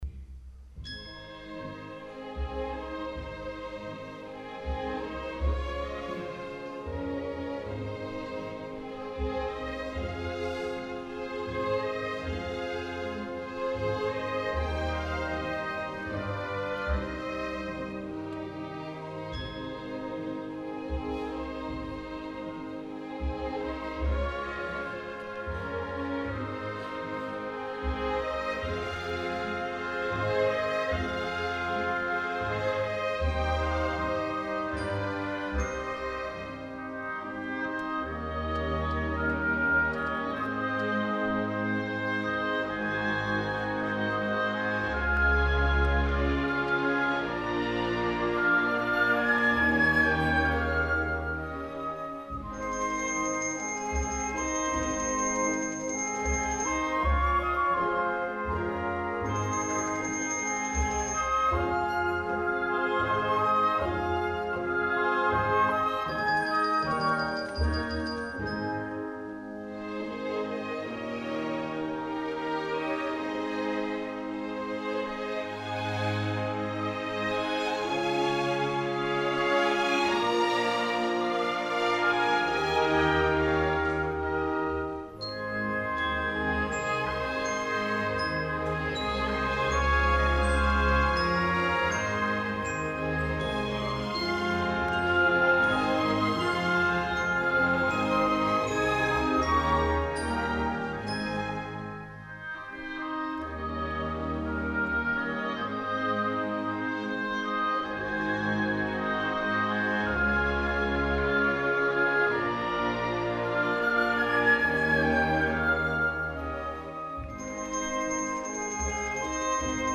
Waltz op.39, nr.15 για Ορχήστρα (live)
Brahms-Waltz-op.39.mp3